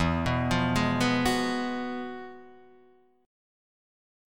Fdim9/E chord {0 2 3 1 0 2} chord